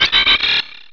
Cri de Maskadra dans Pokémon Rubis et Saphir.